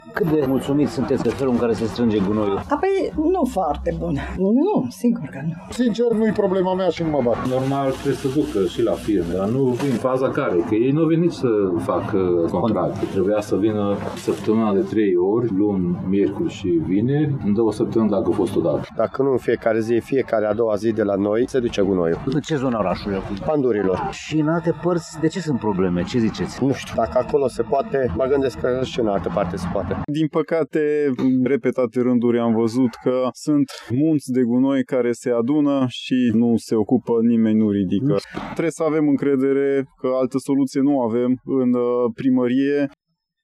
Locuitorii oraşului își plătesc taxa de salubritate şi așteaptă să beneficieze de serviciile pentru care au plătit: